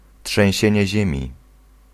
Ääntäminen
IPA: /tʁɑ̃.blǝ.mɑ̃ də.tɛʁ/